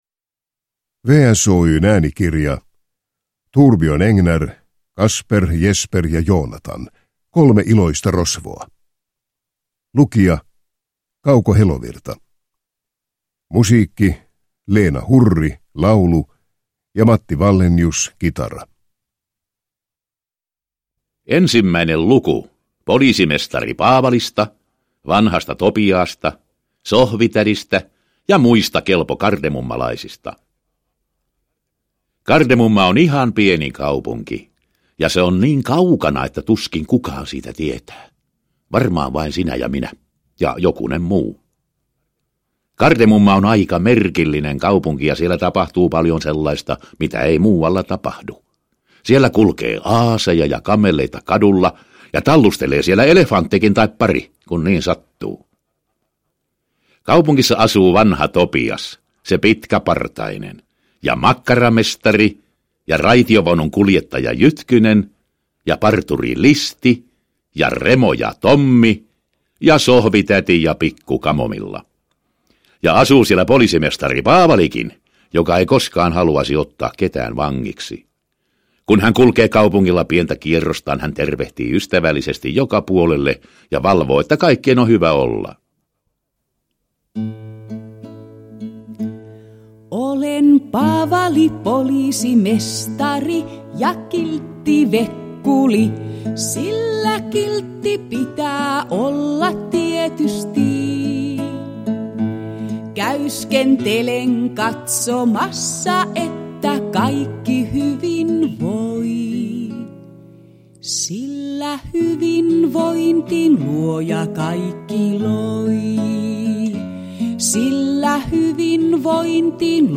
Kasper, Jesper ja Joonatan. Kolme iloista rosvoa (ljudbok) av Thorbjørn Egner